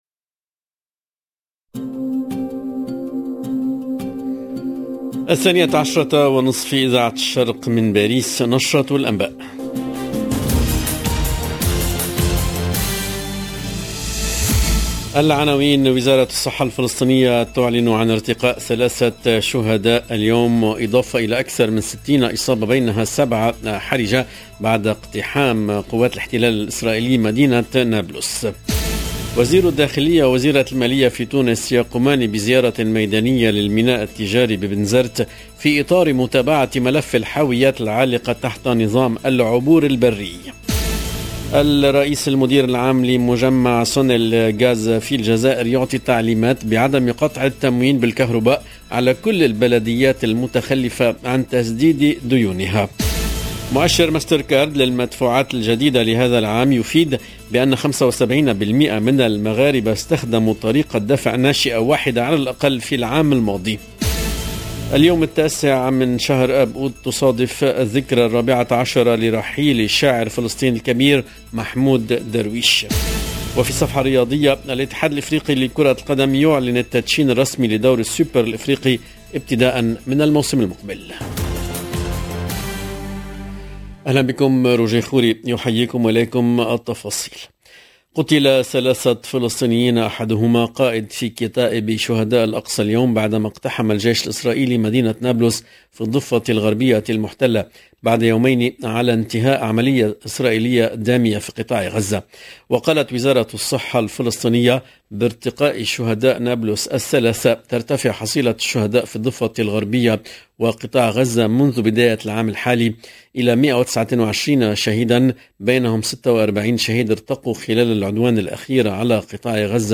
LE JOURNAL EN LANGUE ARABE DE MIDI 30 DU 9/08/22